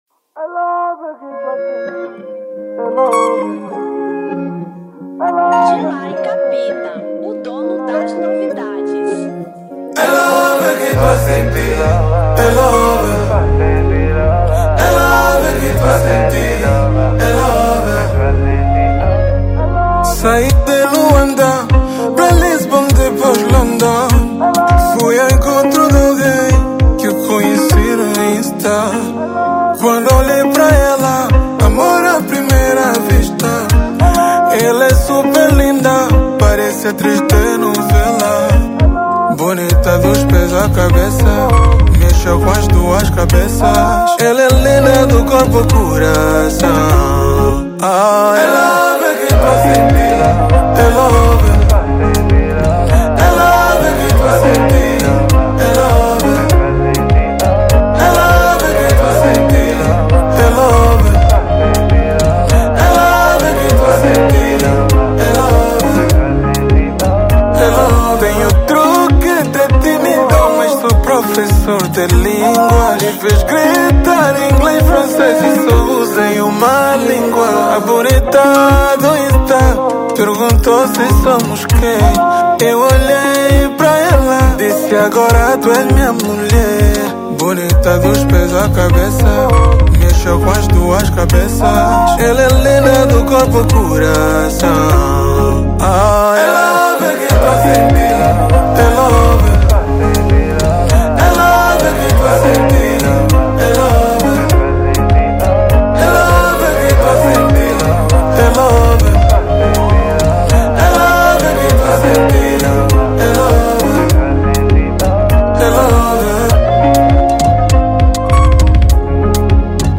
Kizomba 2024